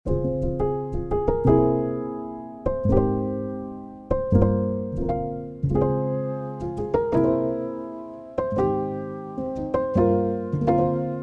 さりげないテープ・サチュレーション、霞んだリバーブ・テール、ビットクラッシュされたブレイクダウンまで、RC-20は常に“実験的な音作り”を誘います。